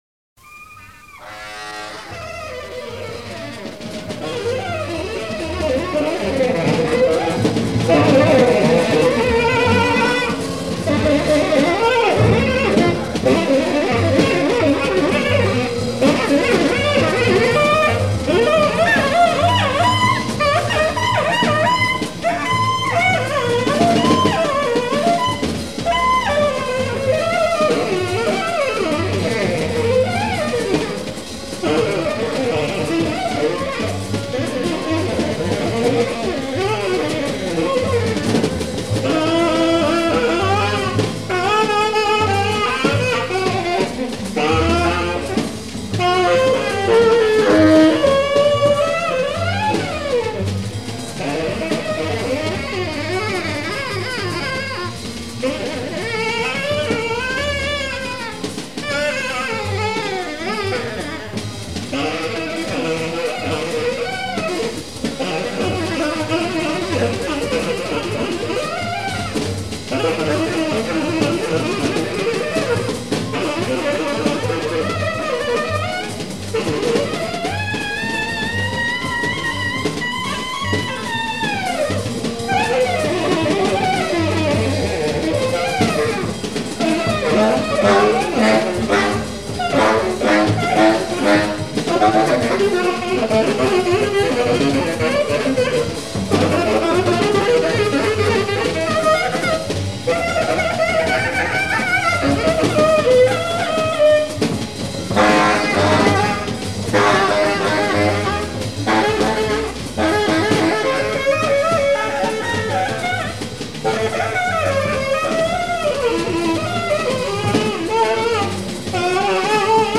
Essentially a live recording
at the Cellar Cafe, New York on 14th June, 1964